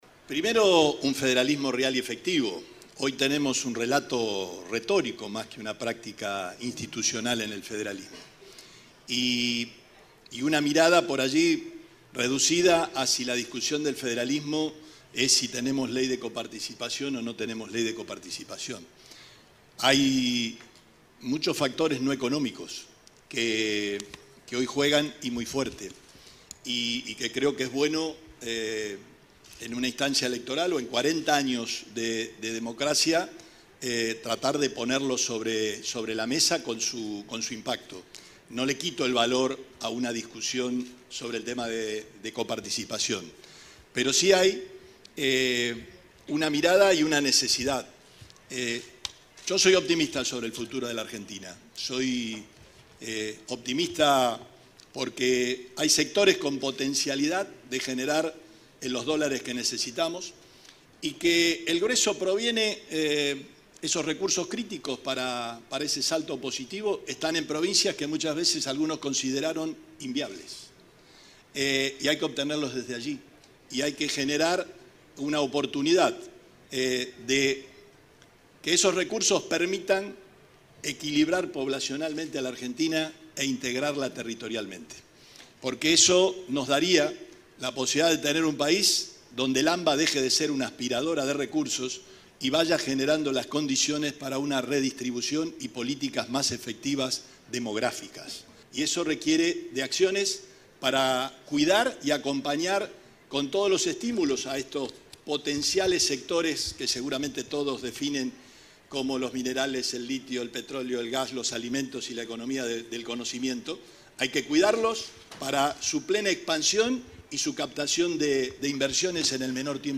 El gobernador Omar Perotti participó este martes del AmCham Summit 2023, un encuentro empresario con referentes del sector público y privado, organizado por la Cámara de Comercio de los Estados Unidos en Argentina, bajo el lema “Protagonistas de la próxima Argentina”.
Declaraciones Perotti